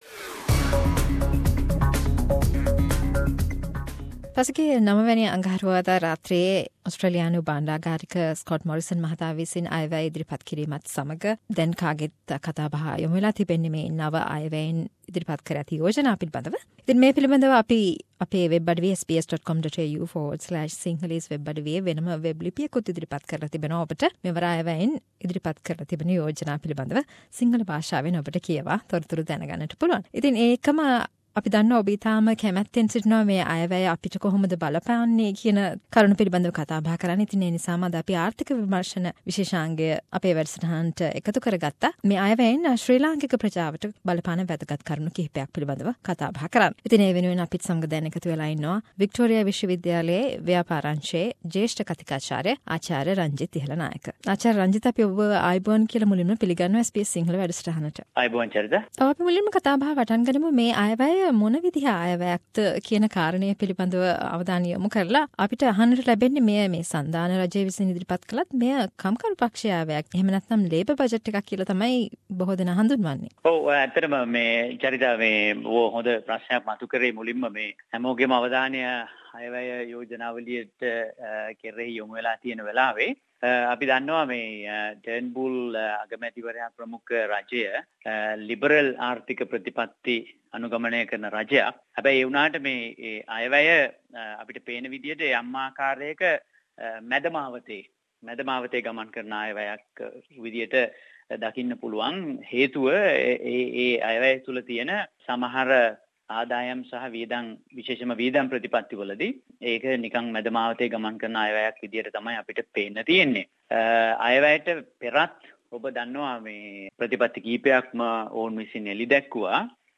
A discussion on the 2017 budget